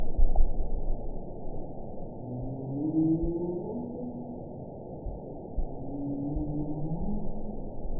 target species NRW